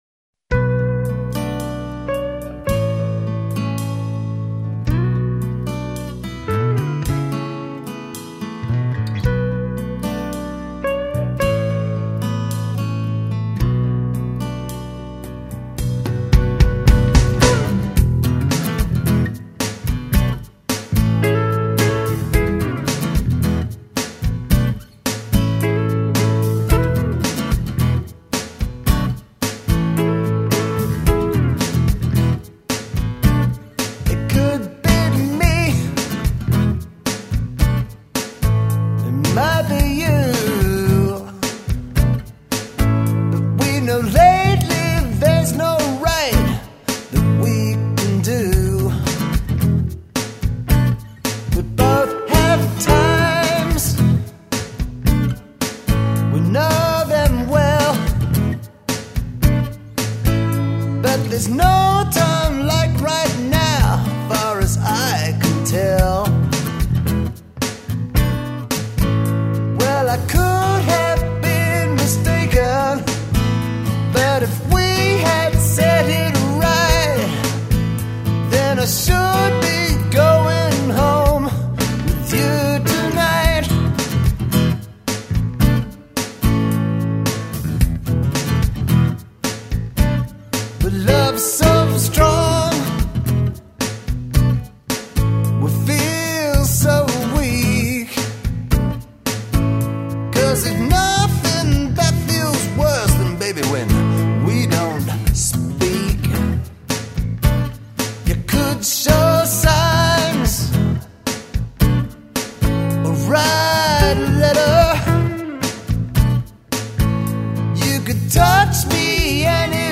piano player and singer/song writer